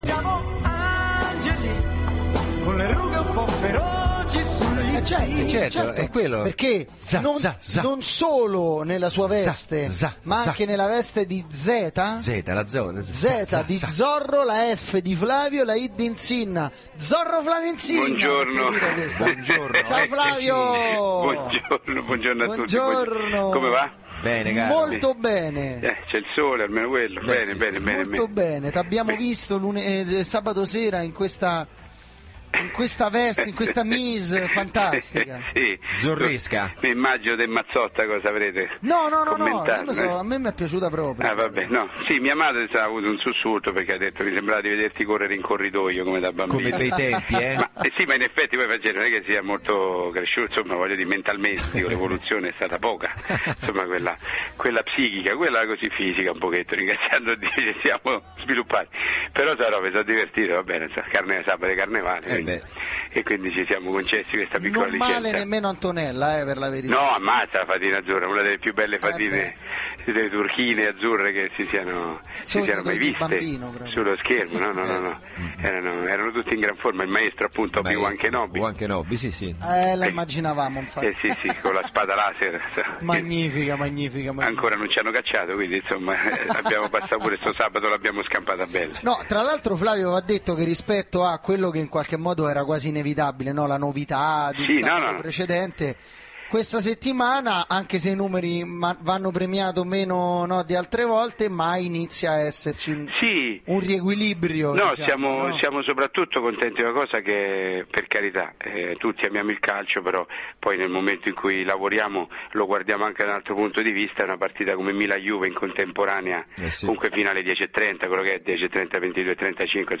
Intervento telefonico Flavio Insinna del 07/03/2011